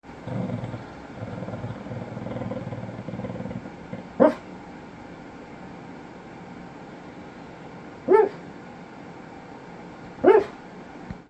Sleep Woofs (Barley, Feat. Air Purifier)
I credit having been able to capture this rare footage to the loud air purifier that was running at the time, which no doubt masked the gentle rustling of my clothes as I turned to face her. This, unfortunately, provides the backing track to Barley’s announcements.
Barley-SleepWoofs.mp3